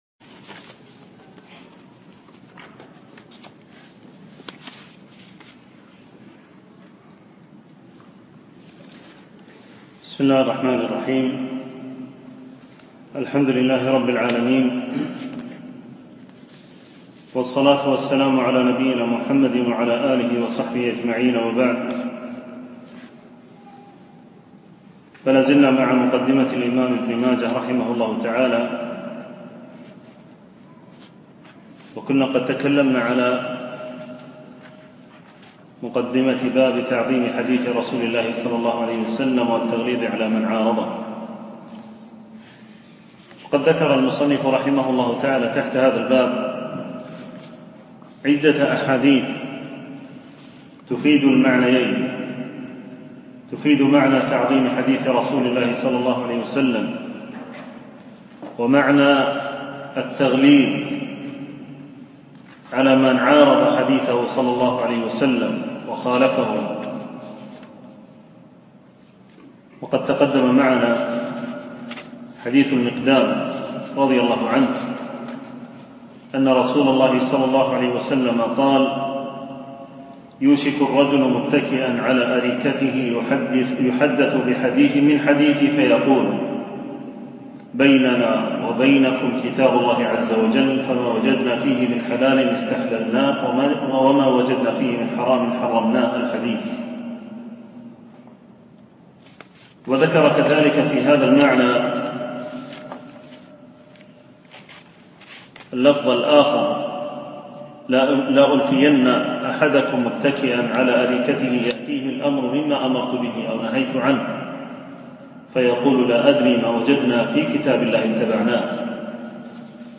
التنسيق: MP3 Mono 11kHz 32Kbps (CBR)